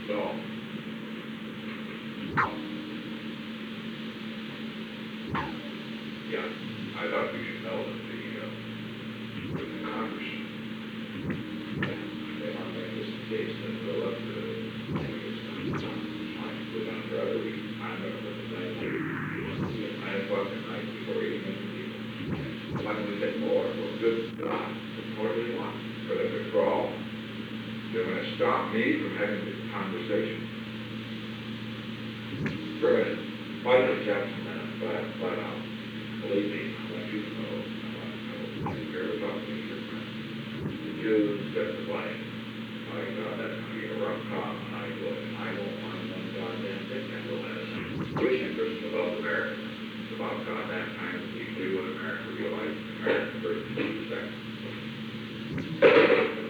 Secret White House Tapes
Conversation No. 429-12
Location: Executive Office Building
The President talked with Henry A. Kissinger.